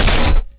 impact_projectile_metal_006.wav